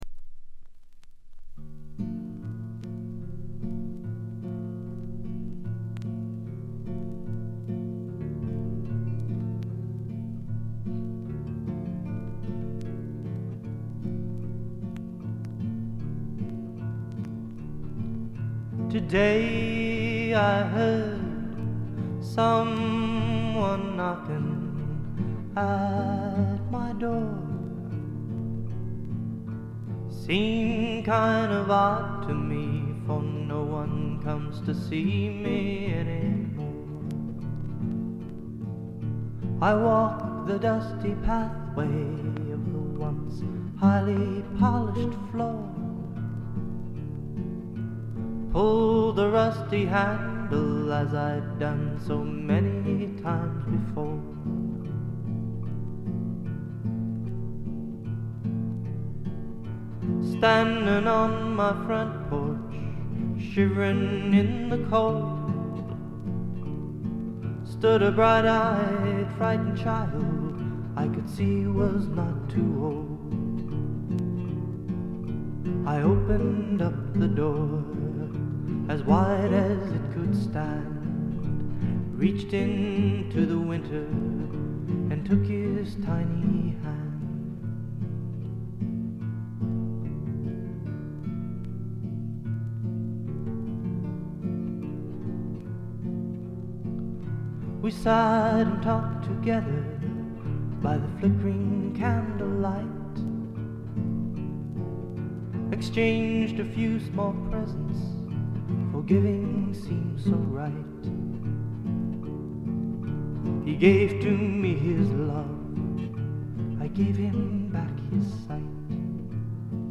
わずかなノイズ感のみ。
全10曲すべて自作、ほとんどギターの弾き語りで、しみじみとしたロンサムな語り口が印象的です。
試聴曲は現品からの取り込み音源です。
Guitar, Harmony Vocals